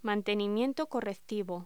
Locución: Mantenimiento correctivo
voz